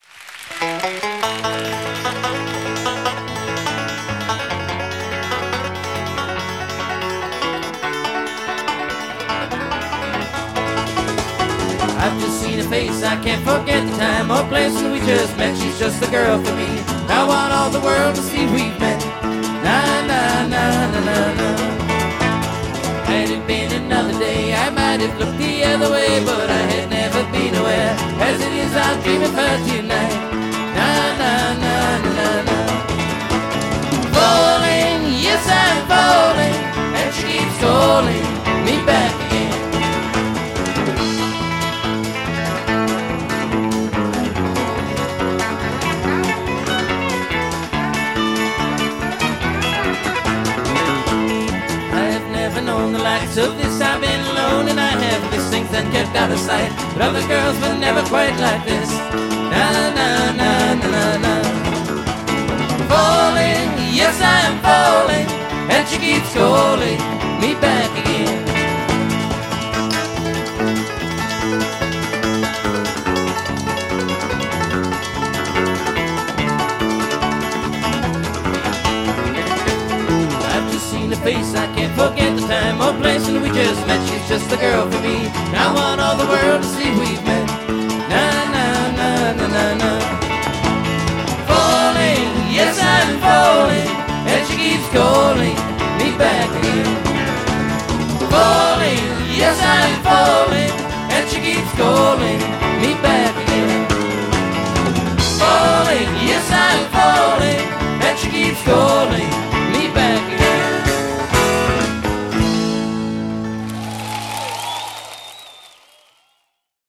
a fine bluegrass romp
old time country treatment